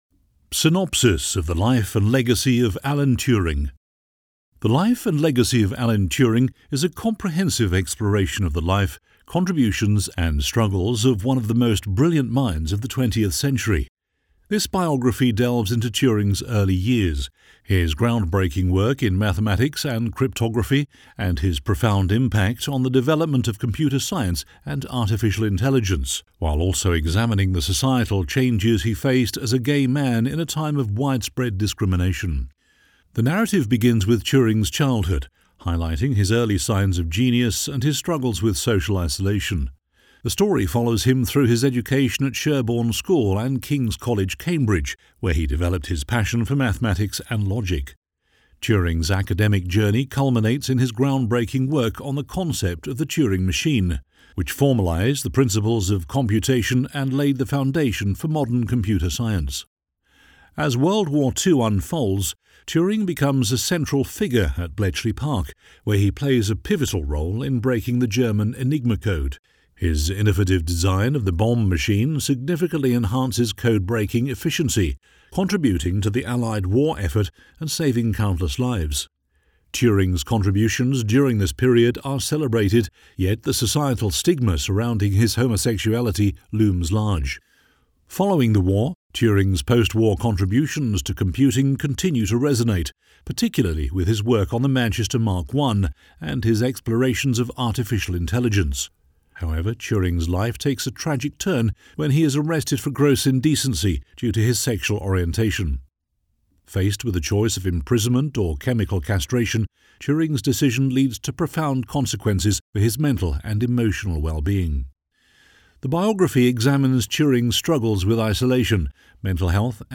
Never any Artificial Voices used, unlike other sites.
Adult (30-50)
Male Voice Over Talent